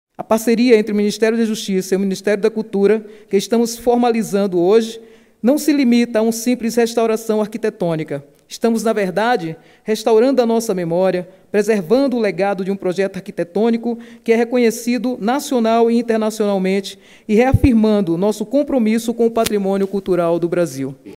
Fala da ministra da Cultura, Margareth Menezes, no lançamento dos projetos de Restauração do Palácio da Justiça e do Centro de Memória do MJSP.mp3 — Ministério da Justiça e Segurança Pública